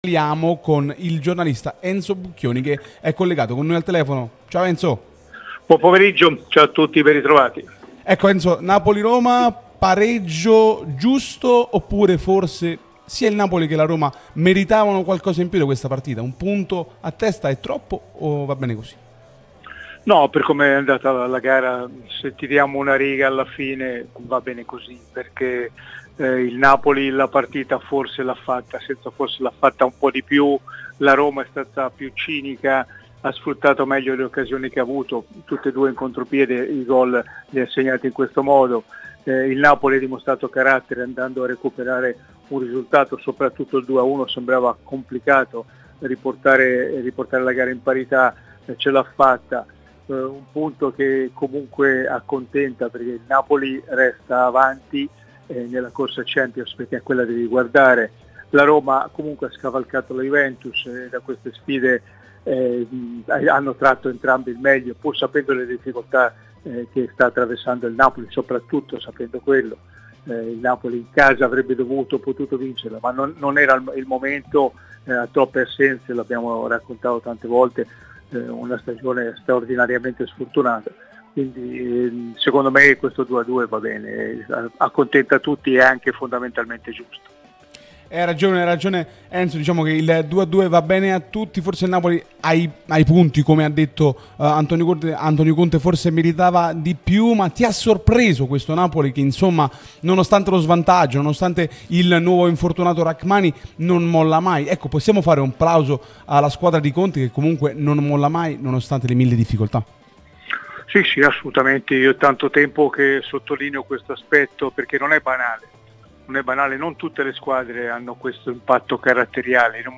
noto giornalista, è intervenuto nel corso di 'Napoli Talk', trasmissione sulla nostra Radio Tutto Napoli